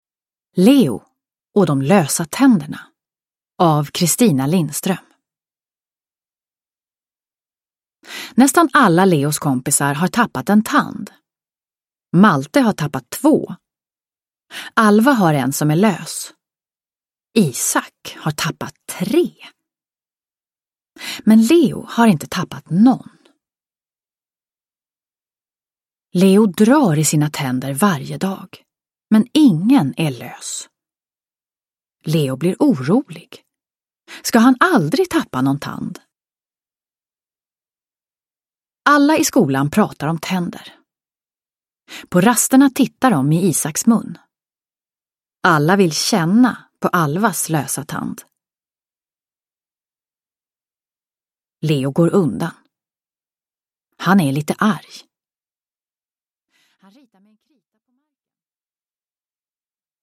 Leo och de lösa tänderna – Ljudbok – Laddas ner